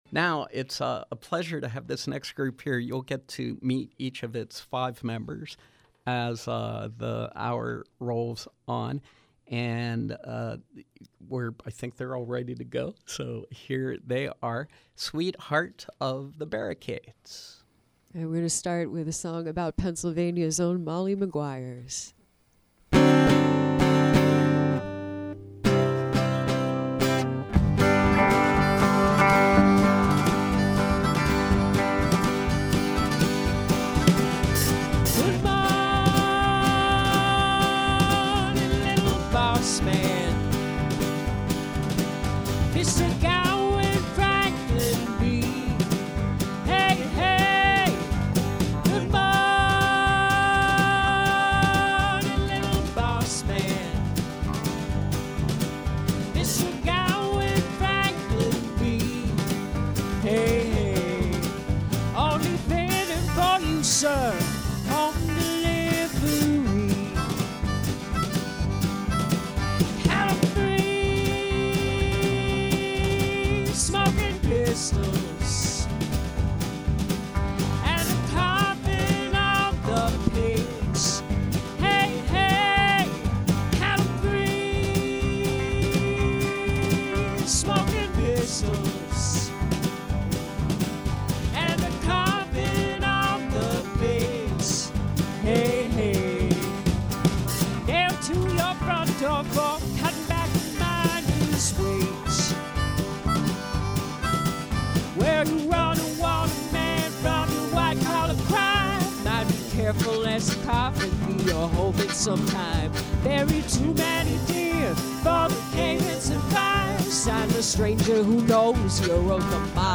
Live performance
alt-country blues group